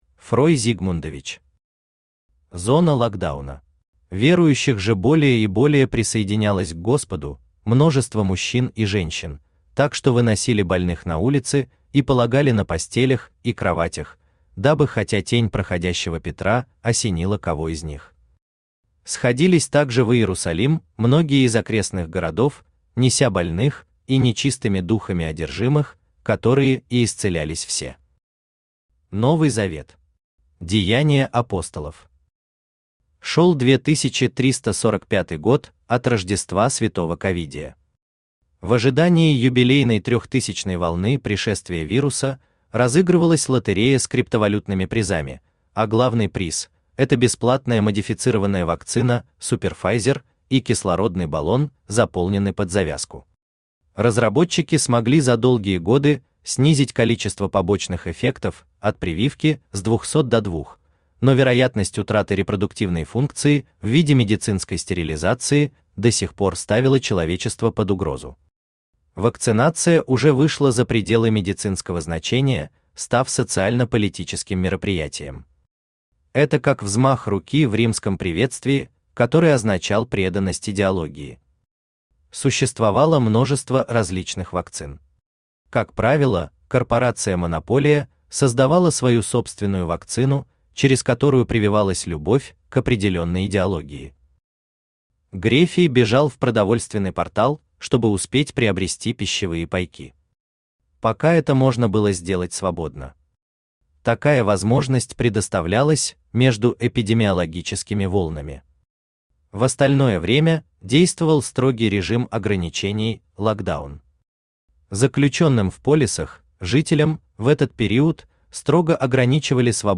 Аудиокнига Зона Локдауна | Библиотека аудиокниг
Aудиокнига Зона Локдауна Автор Фрой Зигмундович Читает аудиокнигу Авточтец ЛитРес.